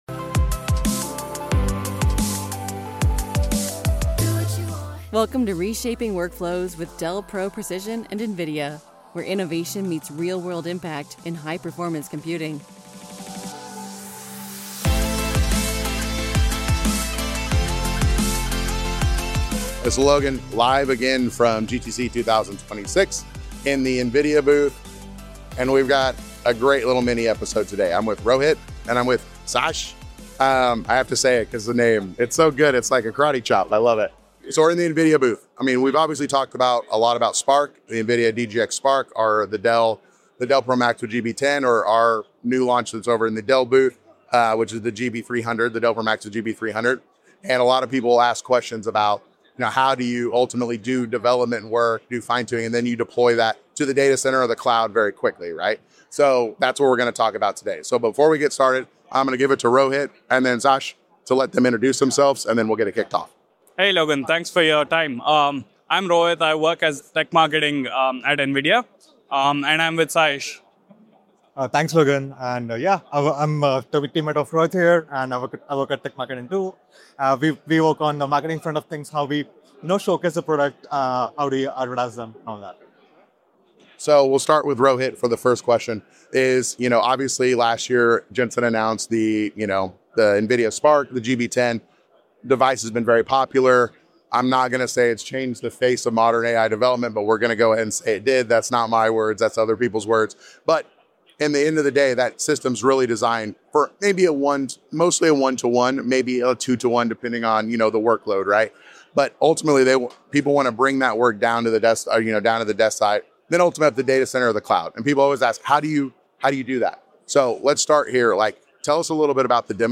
Live from GTC: Write Once and Run Anywhere with NVIDIA's cuTile